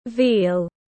Thịt bê tiếng anh gọi là veal, phiên âm tiếng anh đọc là /viːl/
Veal /viːl/